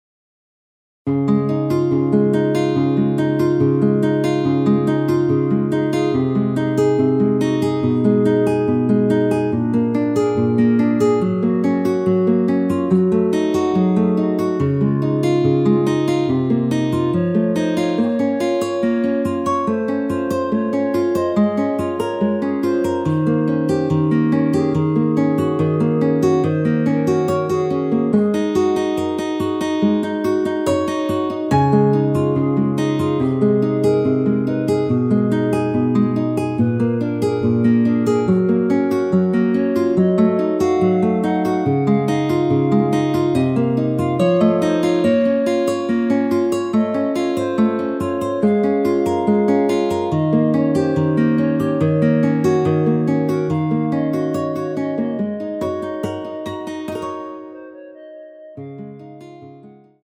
원키에서 (+4)올린 멜로디 포함된 MR 입니다.
Db
앞부분30초, 뒷부분30초씩 편집해서 올려 드리고 있습니다.
중간에 음이 끈어지고 다시 나오는 이유는